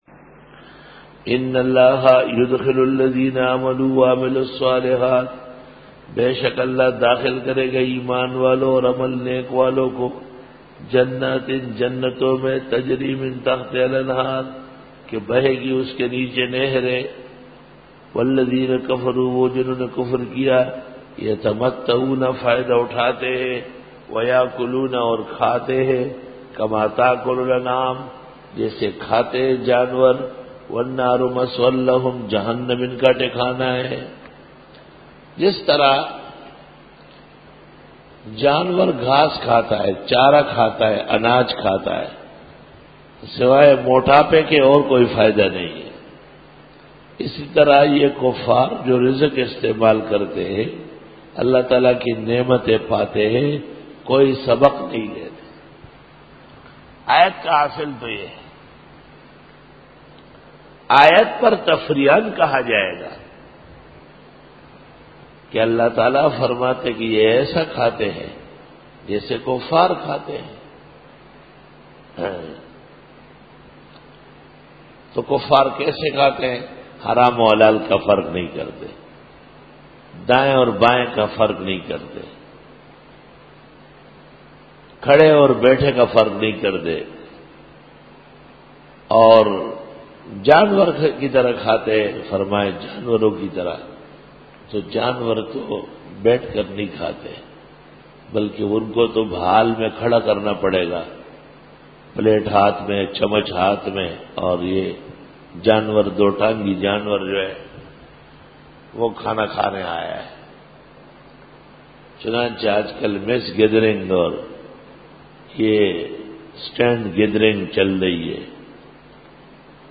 سورۃ محمدرکوع-02 Bayan